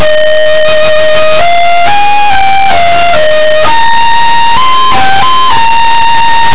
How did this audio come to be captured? I think I start to notice the distortion (in the form of added static-like noice) about flute5.wav. But I find it astonishing just how well you can hear the music when only ONE bit is used to represent the amplitude.